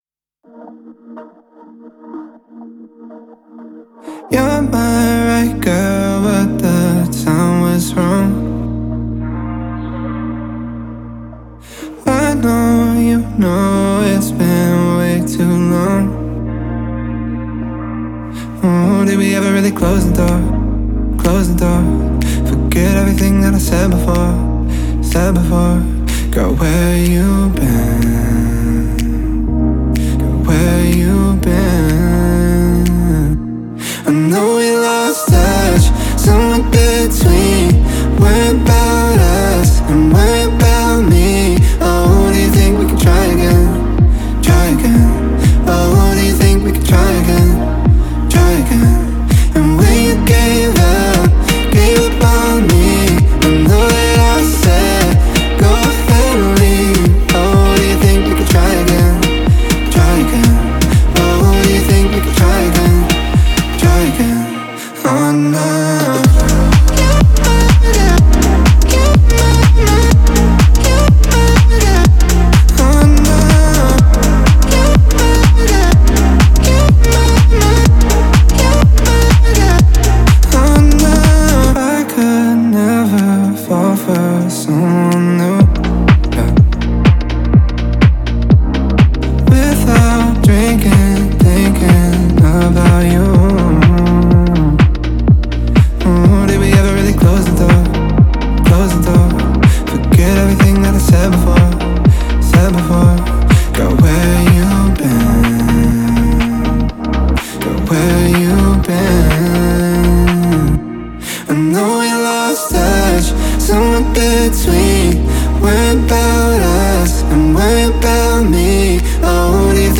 энергичная электронная поп-песня